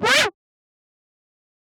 fx (ScratchScream).wav